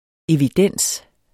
Udtale [ eviˈdεnˀs ]